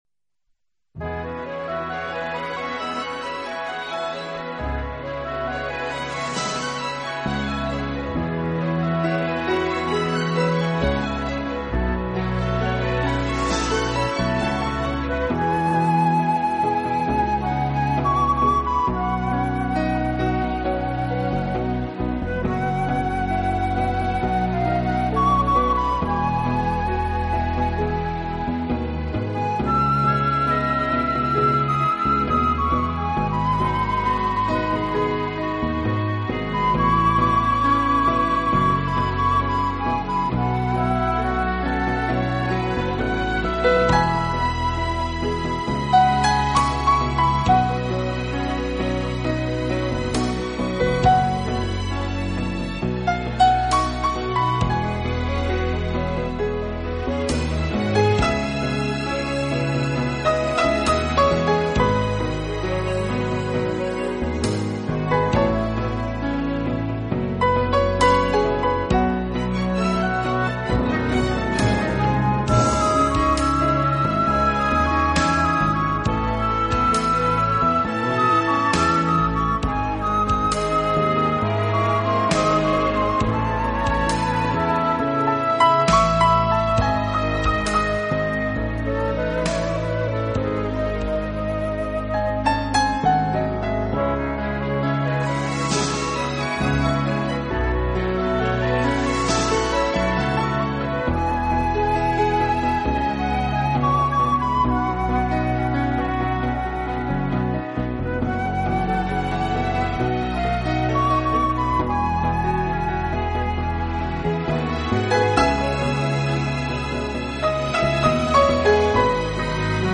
并搭配了75人编制的大型管弦乐团